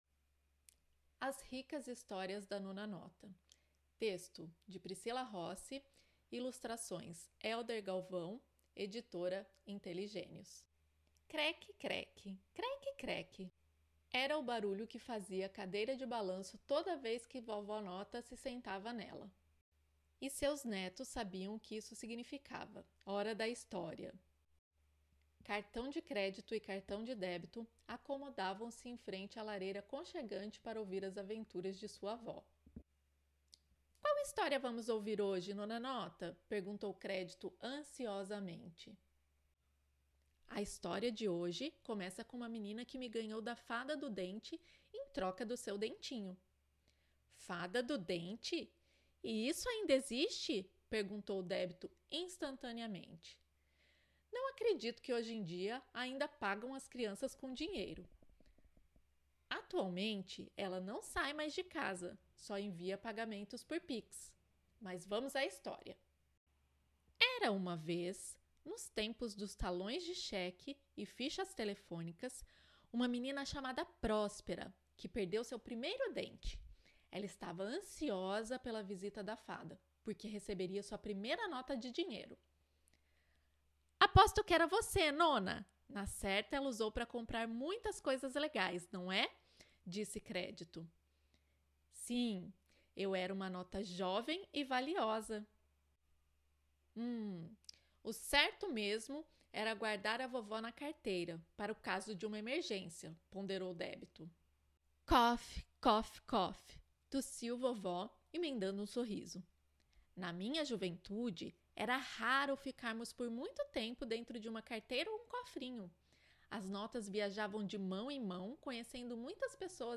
Leitura Guiada